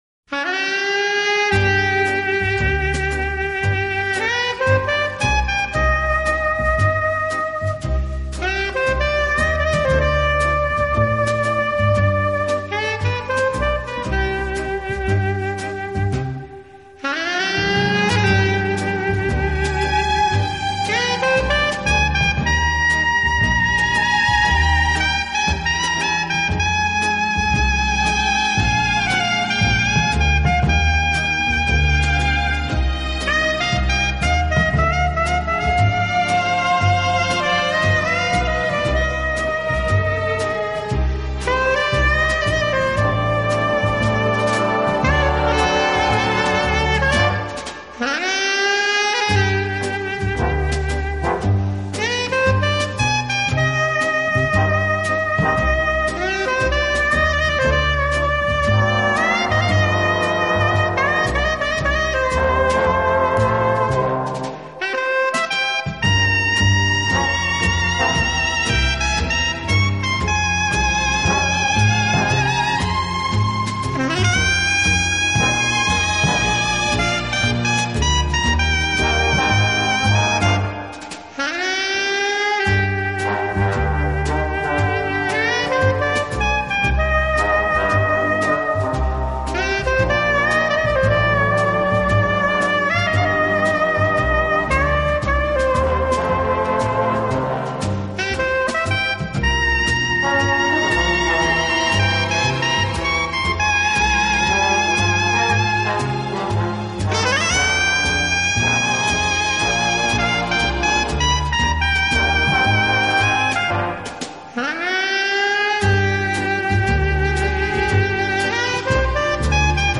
类别：轻音乐
欧洲著名的轻音乐团，以萨克斯管为主，曲目多为欢快的舞曲及流行歌曲改
编曲。演奏轻快、 柔和、优美，带有浓郁的爵士风味。